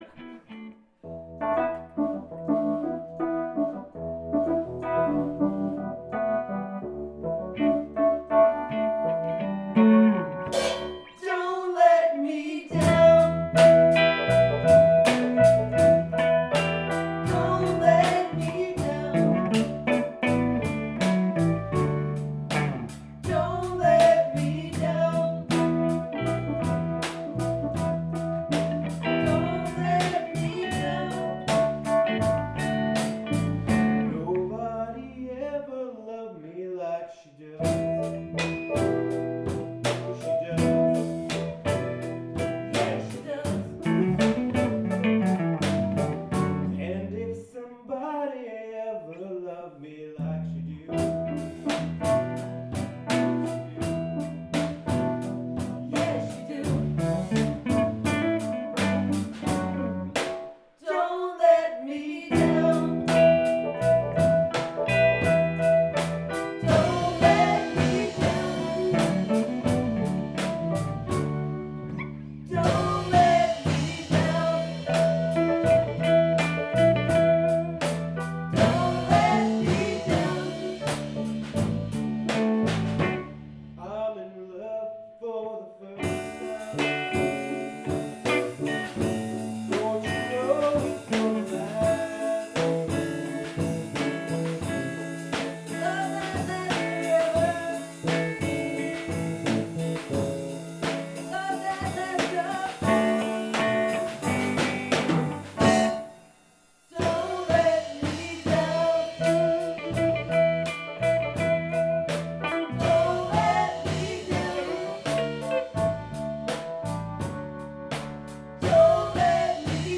singer/bass guitar
Singer/Lead guitar
drummer
Keyboards